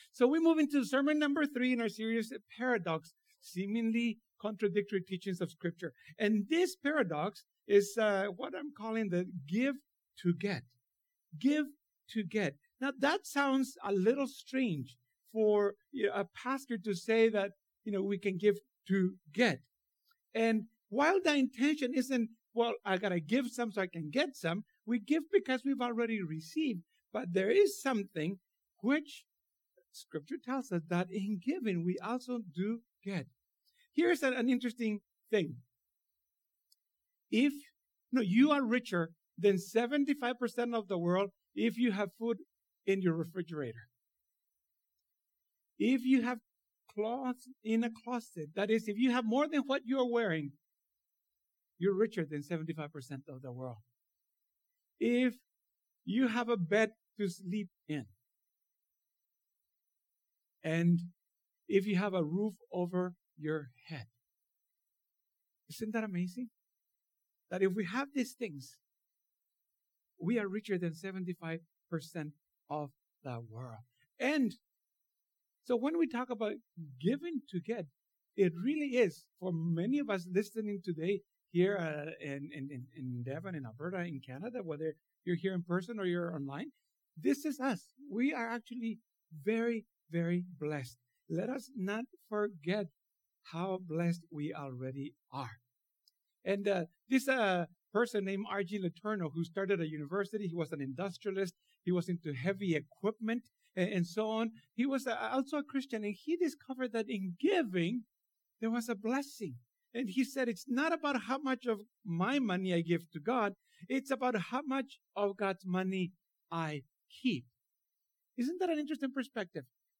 This is sermon number three in this series.